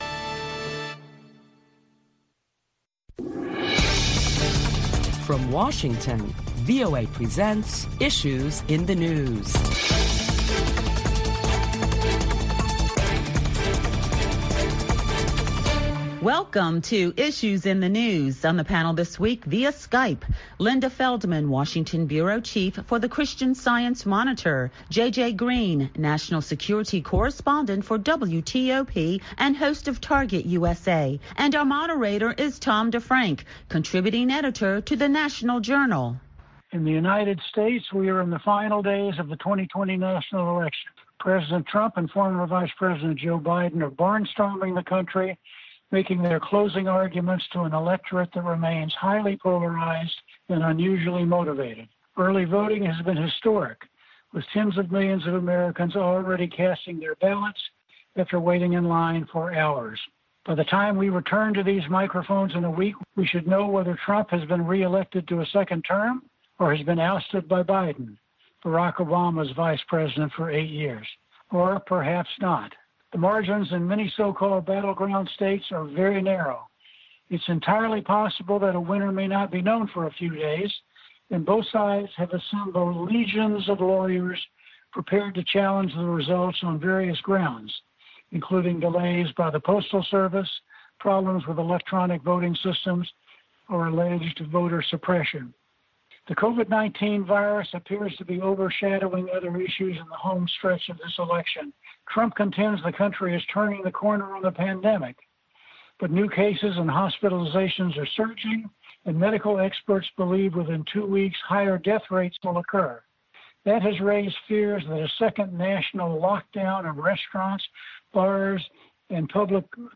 A panel of prominent Washington journalists deliberate the latest top stories of the week including the US Senate approving President Trump's Supreme Court nominee, Judge Amy Coney Barrett.